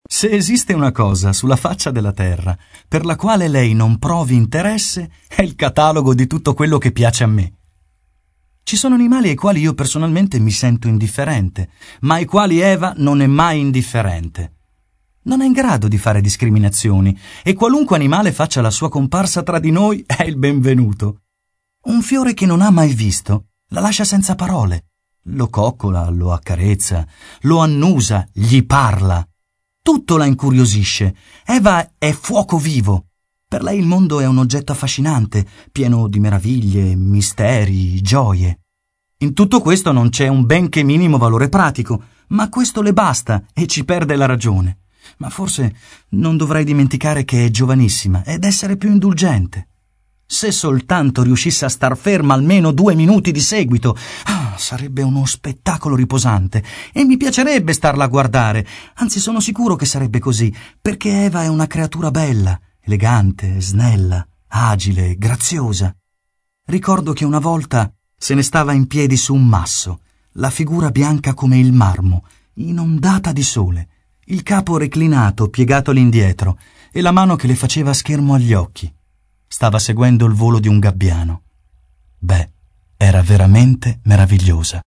Demo Audio Pubblicità Voiceover